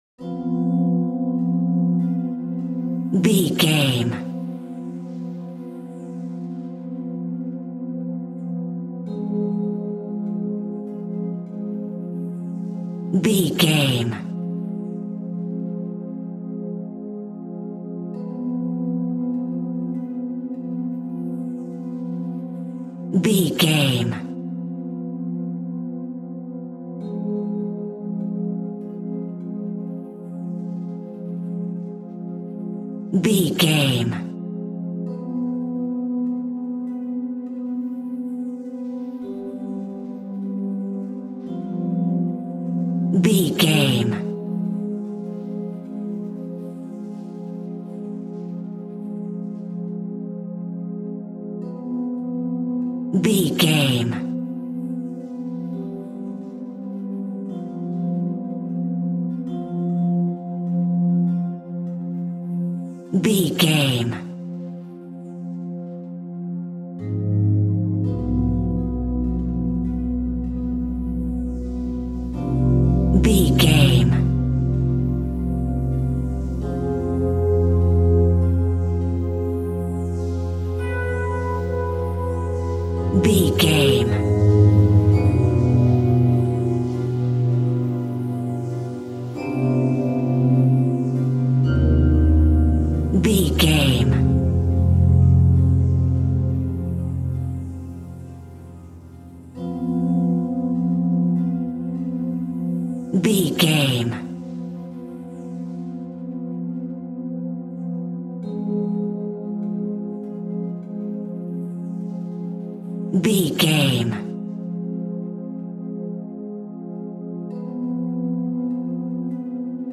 Space Horror Music Cue.
Aeolian/Minor
Slow
ominous
eerie
synthesizer
Horror synth
Horror Ambience